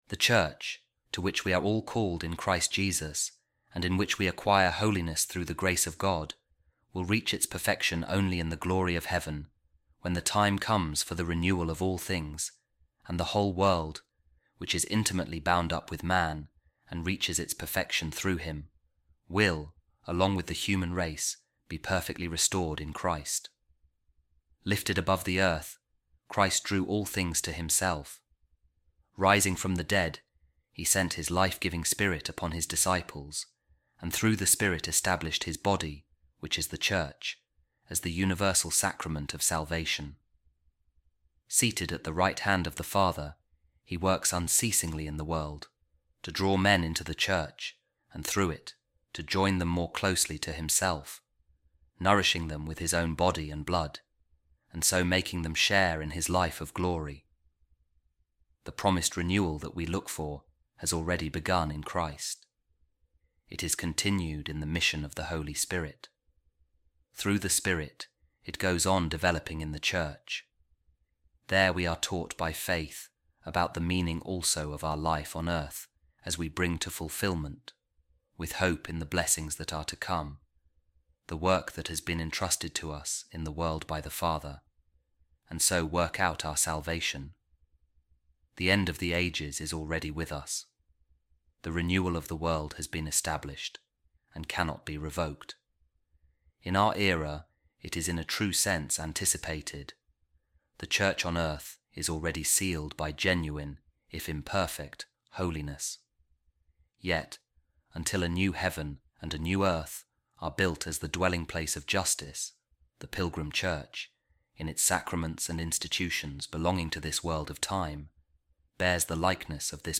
A Reading From Lumen Gentium, The Second Vatican Council’s Dogmatic Constitution On The Church | The Eschatological Character Of The Pilgrim Church